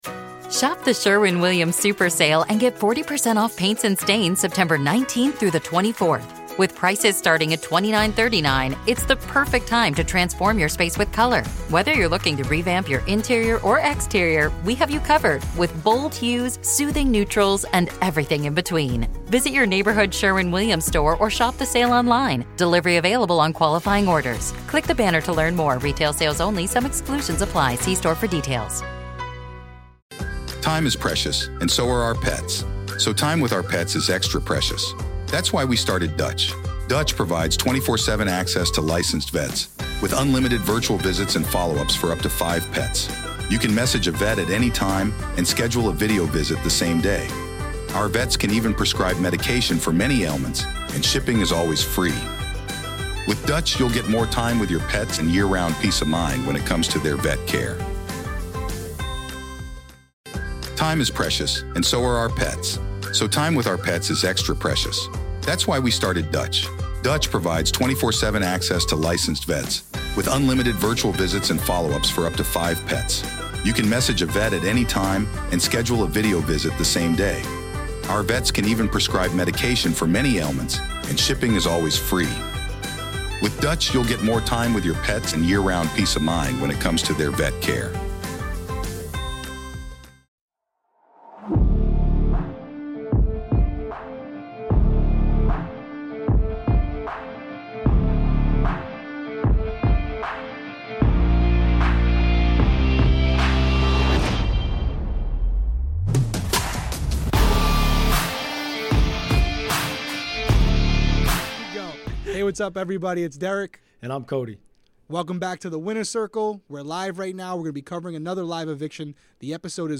On this LIVE recap of the week 2 eviction